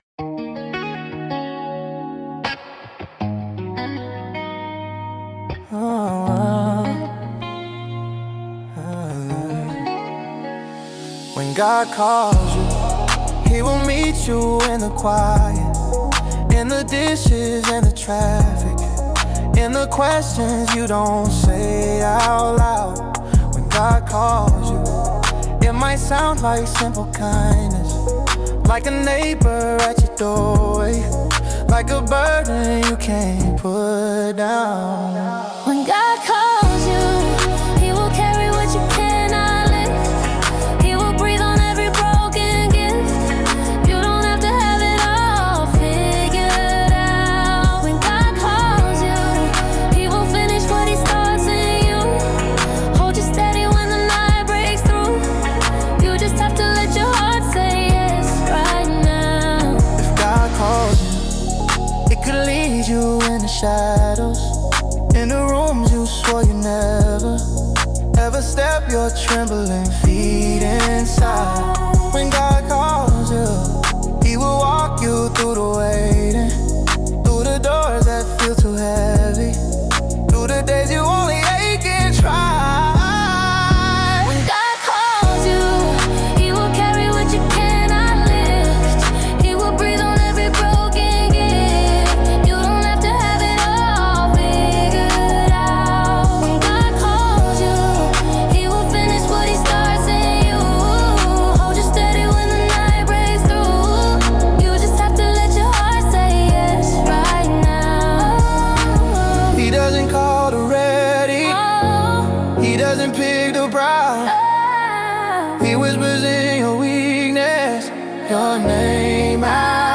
Genre: Gospel / Spiritual / Chant